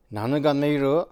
Type: Single wh-question
Final intonation: Rising
WhP Intonation: Peak
Location: Showamura/昭和村
Sex: Male